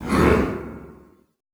Index of /90_sSampleCDs/Best Service - Extended Classical Choir/Partition I/DEEP SHOUTS
DEEP HMM  -L.wav